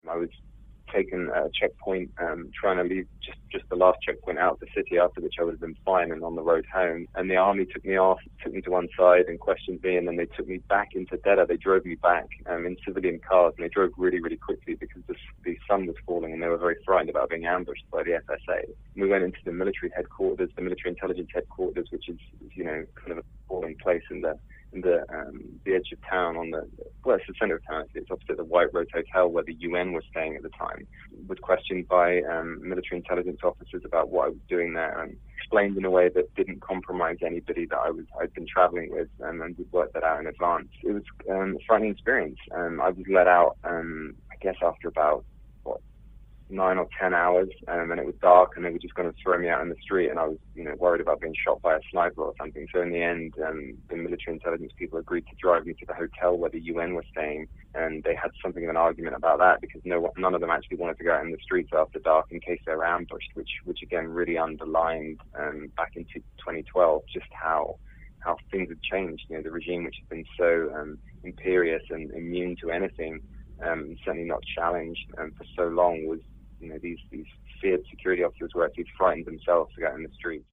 reports from Lebanon.